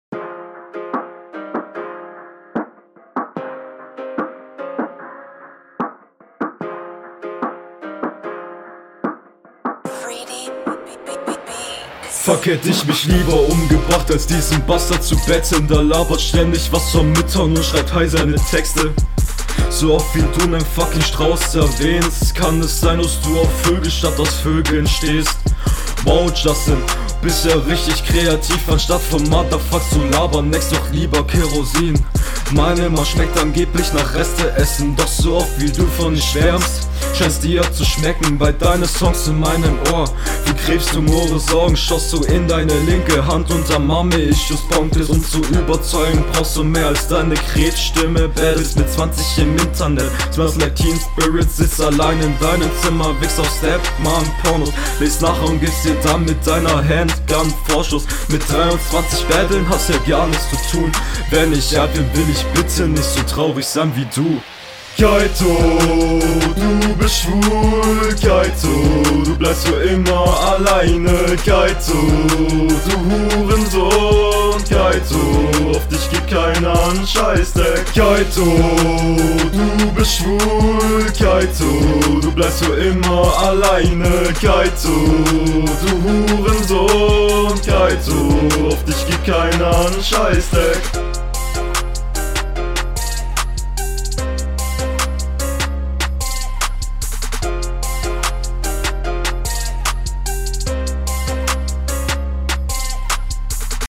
Flow: Flow hier lebendiger als beim Gegner.